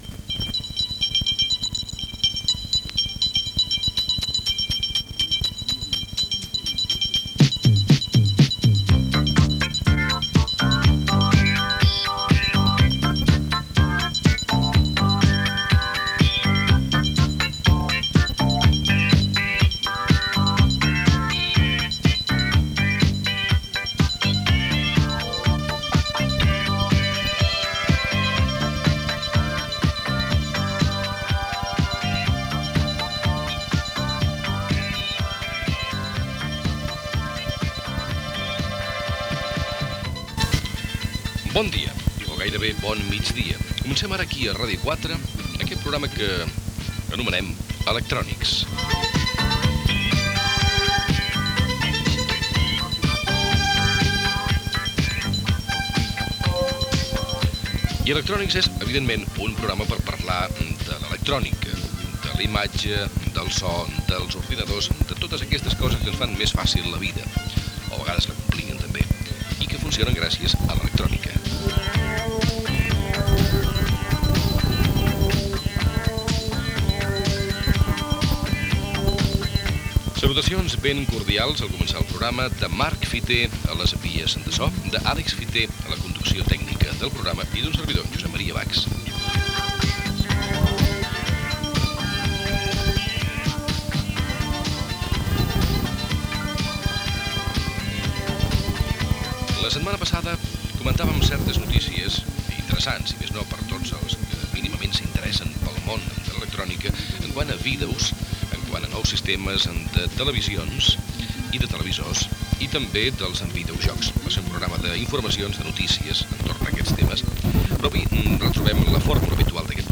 Inici del programa i publicitat.
Divulgació